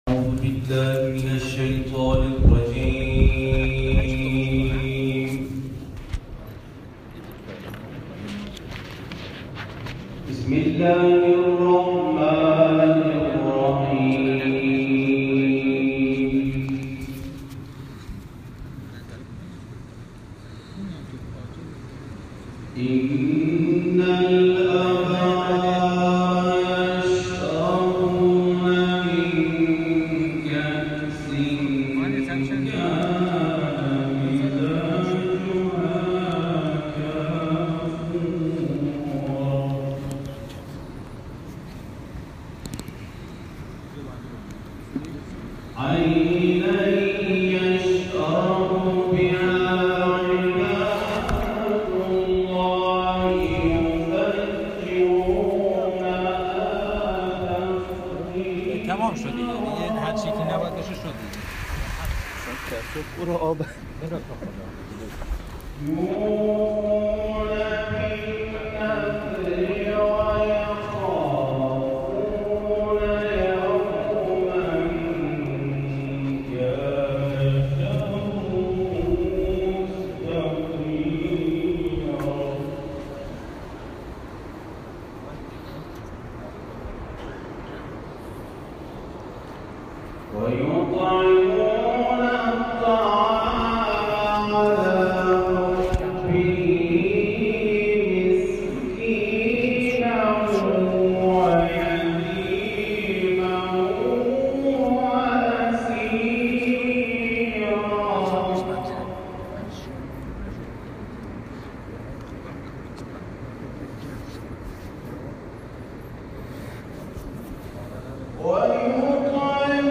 تلاوت
در مهدیه نیروی دریایی بوشهر